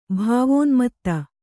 ♪ bhāvōnmatta